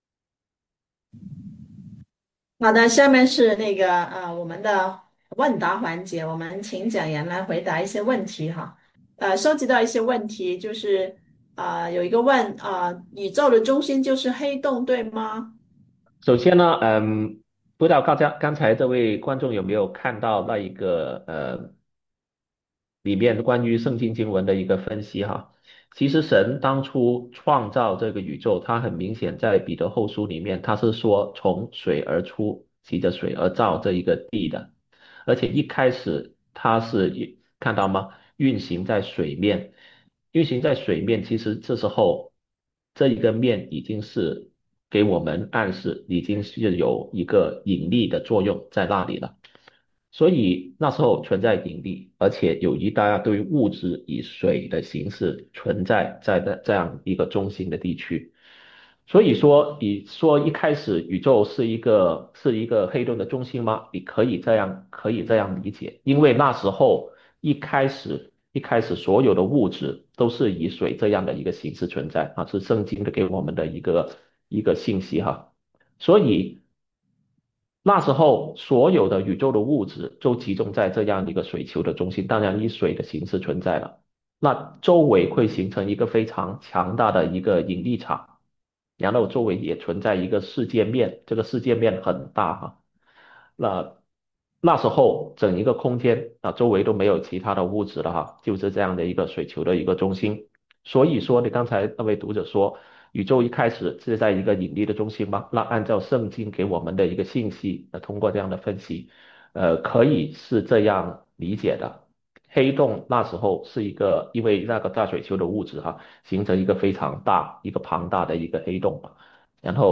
《为何我们能看见几百万光年之外的星光》讲座直播回放
Why-can-we-see-starlight-millions-of-q&a.mp3